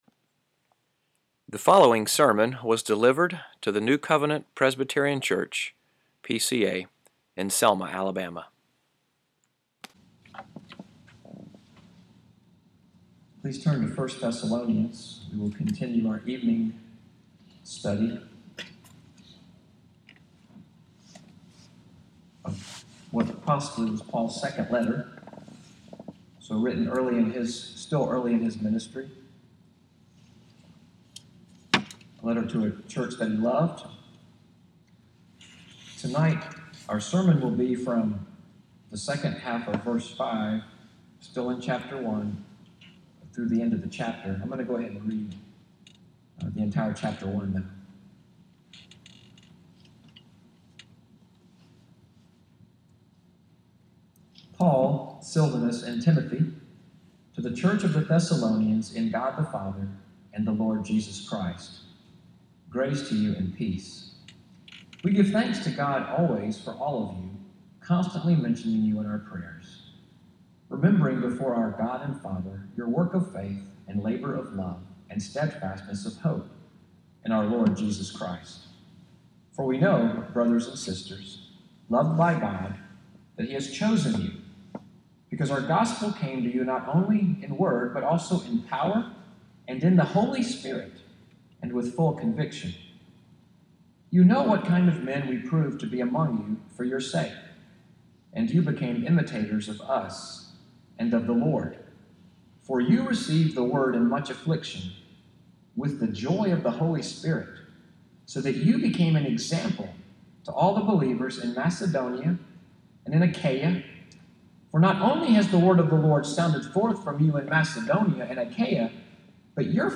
EVENING WORSHIP at NCPC in Selma, AL, audio from the sermon, “The Missionary Church,” July 23, 2017